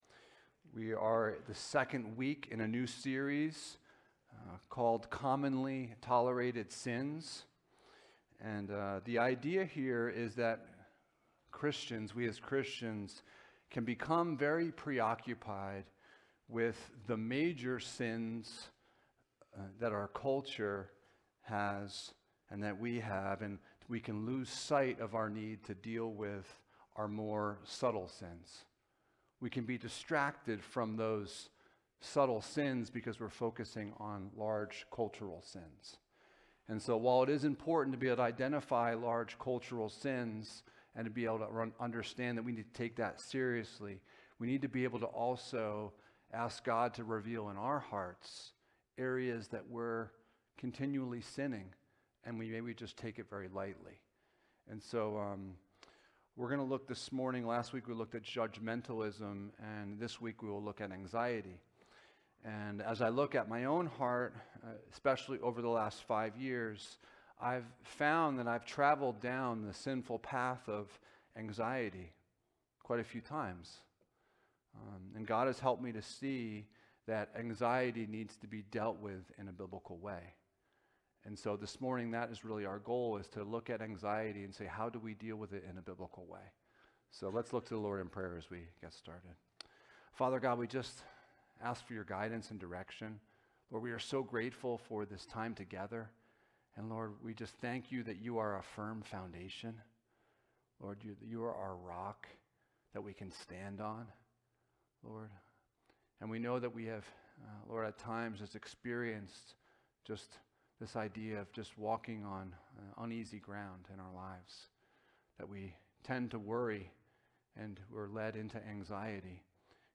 Passage: Philippians 4: 4-7 Service Type: Sunday Morning